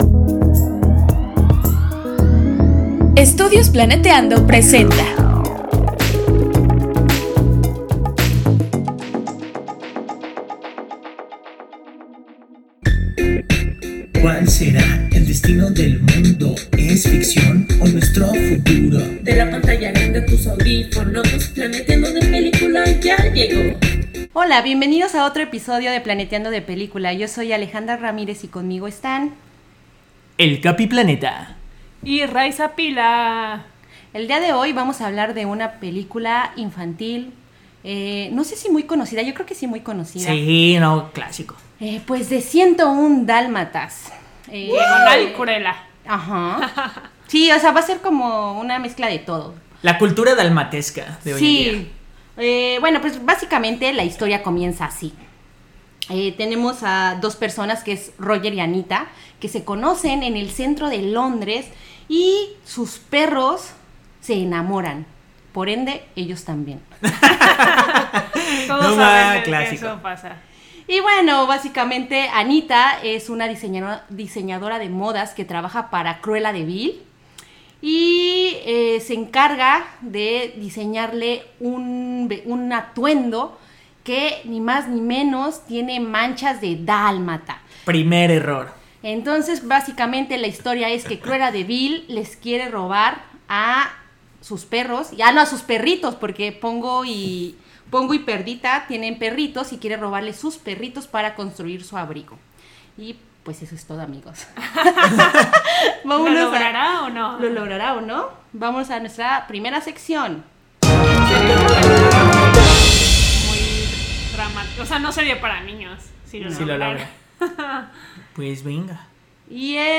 ¡No te pierdas esta gran conversación!